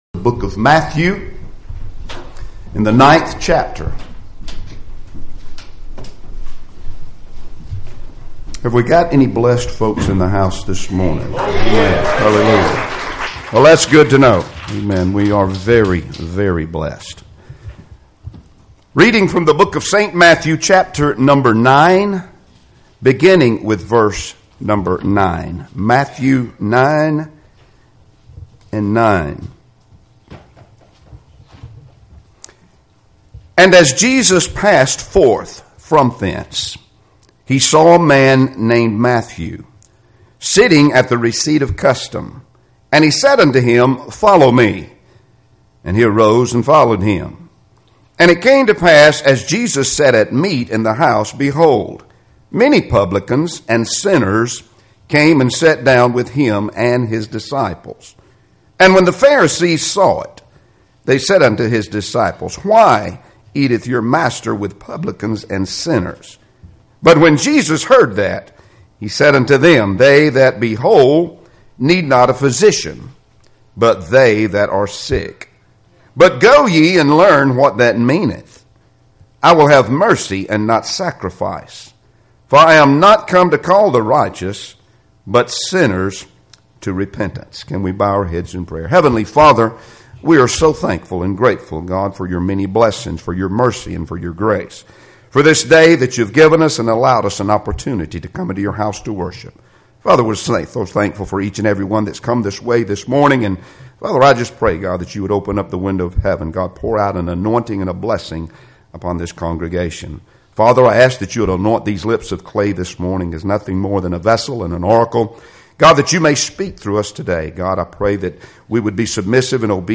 Passage: X Matthew 9:9-13 Service Type: Sunday Morning Services Topics